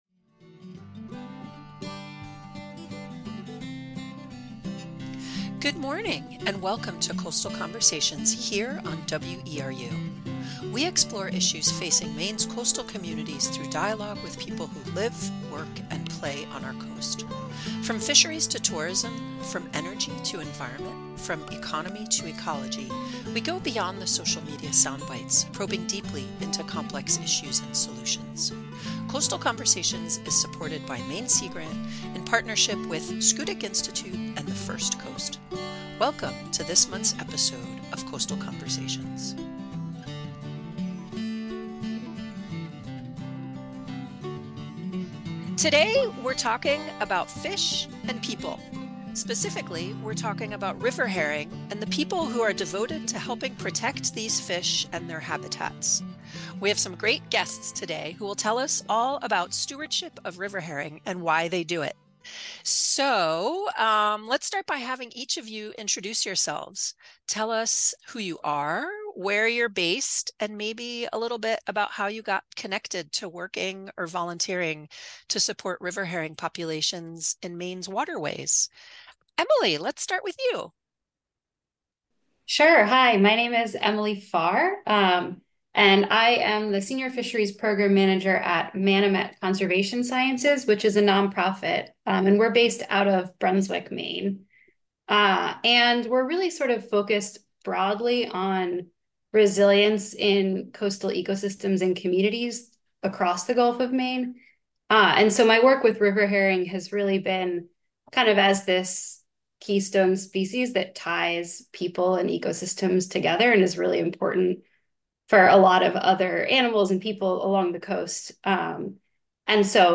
Coastal Conversations: Conversations with people who live, work, and play on the Maine coast, hosted by the University of Maine Sea Grant Program.